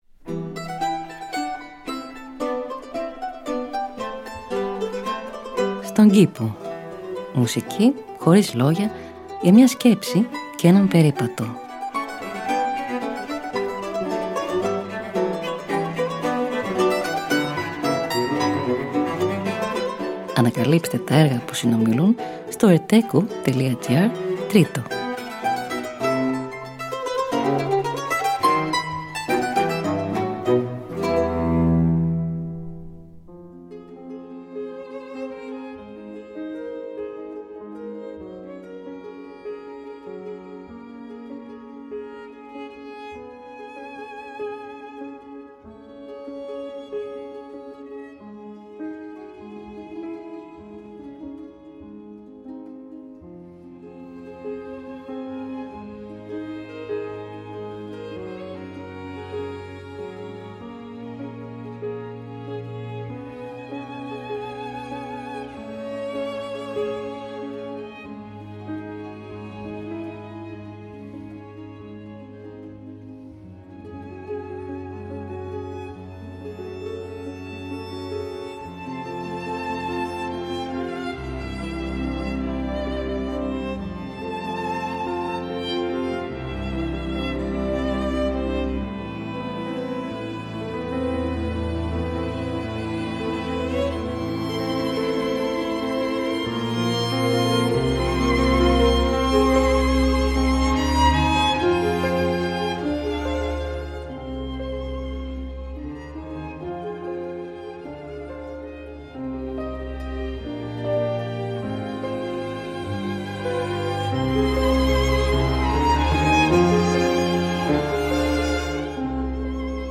Μουσική Χωρίς Λόγια για μια Σκέψη και έναν Περίπατο.